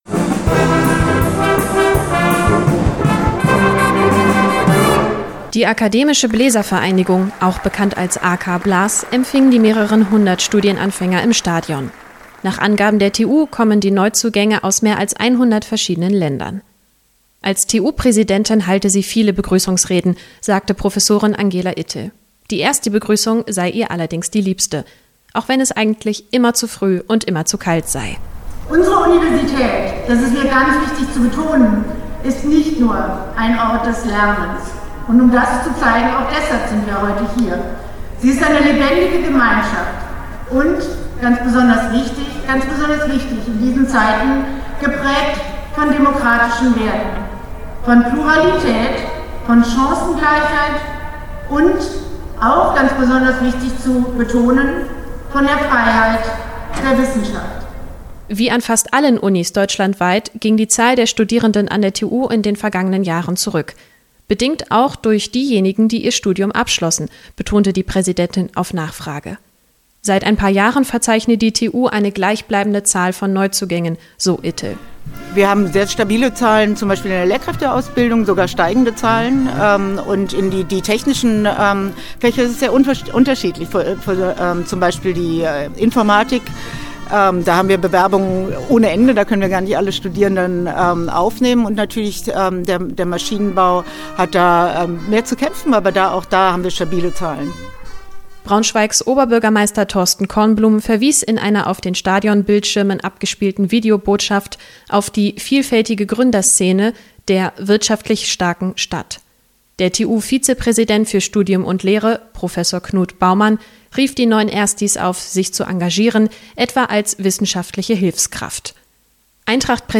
Zum 13. Mal lud die TU die neuen Erstsemester zur Begrüßung ins Eintracht-Stadion ein. Neben Showeinlagen und einem wissenschaftlichen Kurzvortrag gab es für die Erstis vor allem ermutigende Worte im zweisprachig moderierten Programm.